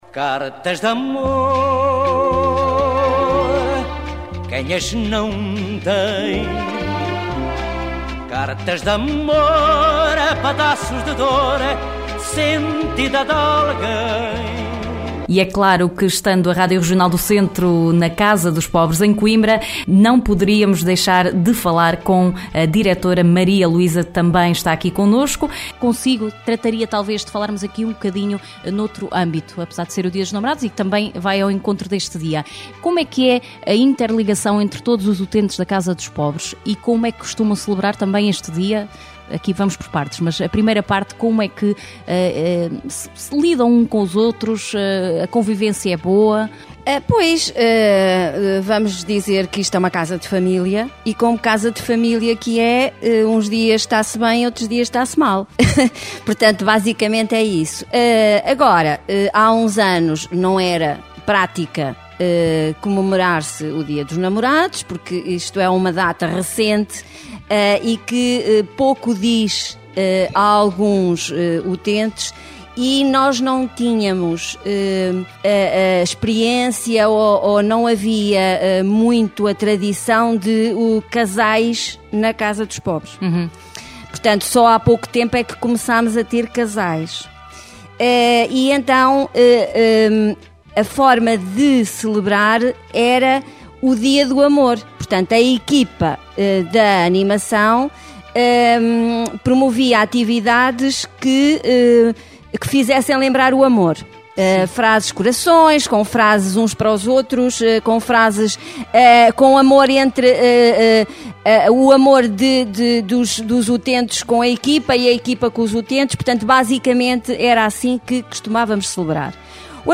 A Regional do Centro foi à Casa dos Pobres, em Coimbra, para saber como se celebrava o Amor noutros tempos.